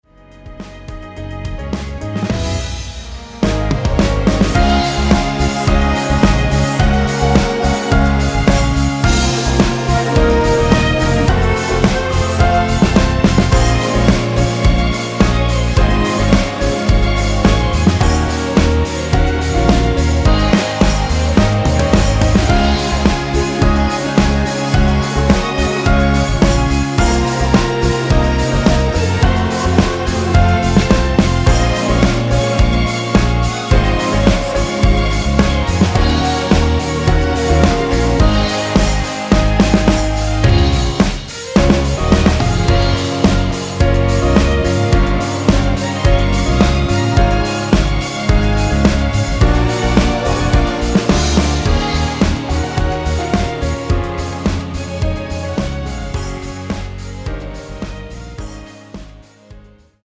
전주 없이 시작 하는곡이라 노래 하시기 좋게 2마디 전주 만들어 놓았습니다.(약 5초쯤 노래 시작)
◈ 곡명 옆 (-1)은 반음 내림, (+1)은 반음 올림 입니다.
앞부분30초, 뒷부분30초씩 편집해서 올려 드리고 있습니다.